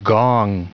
Prononciation du mot gong en anglais (fichier audio)
Prononciation du mot : gong